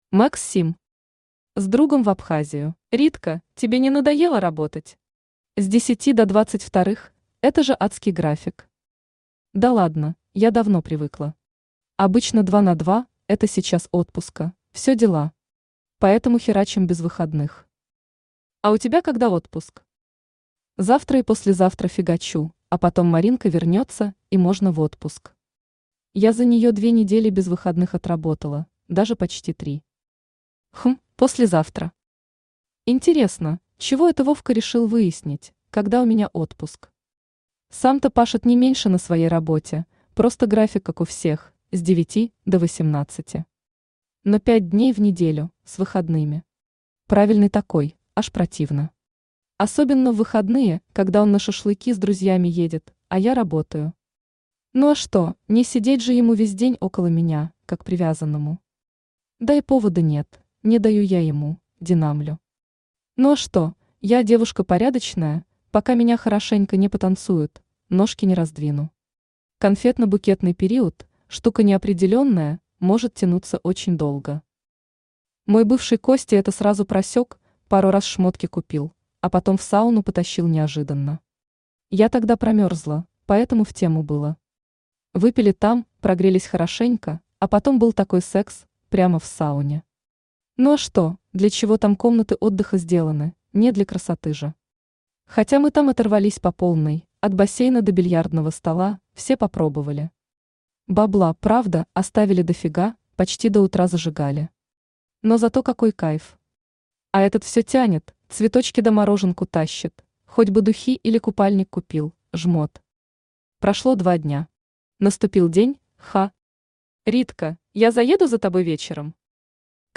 Аудиокнига С другом в Абхазию | Библиотека аудиокниг
Читает аудиокнигу Авточтец ЛитРес.